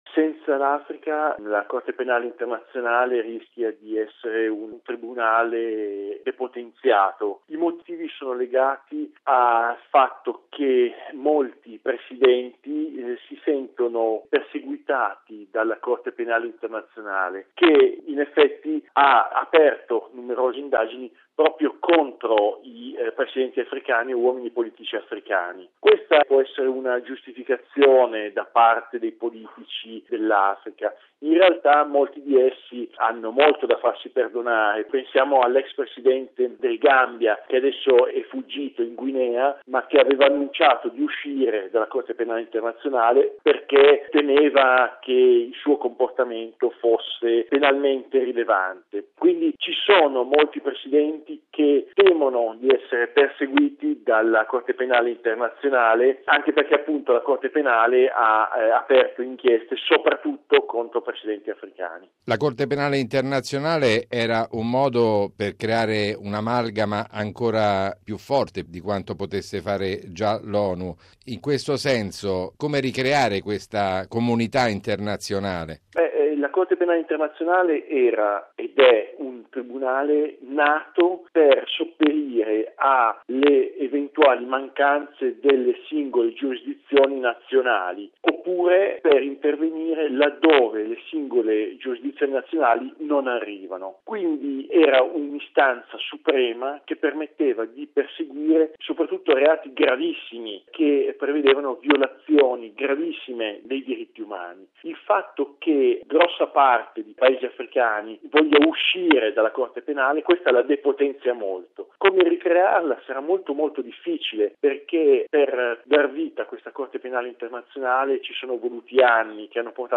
esperto di Africa: